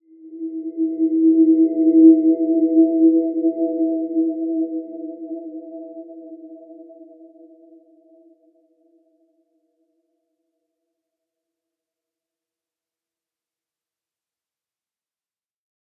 Dreamy-Fifths-E4-mf.wav